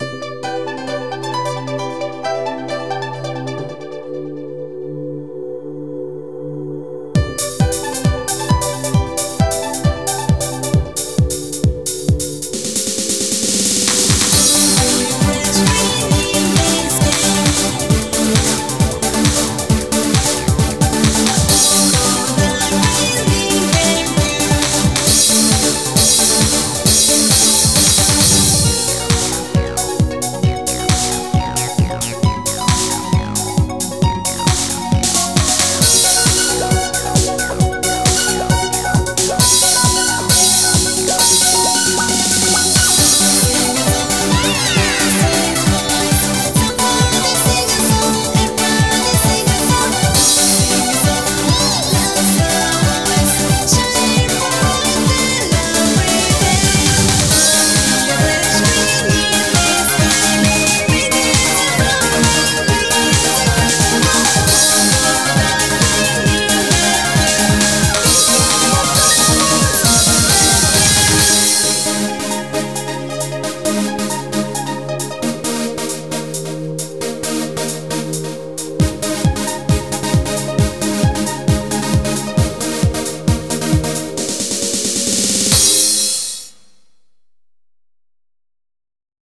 BPM134
Audio QualityPerfect (High Quality)
Better quality audio.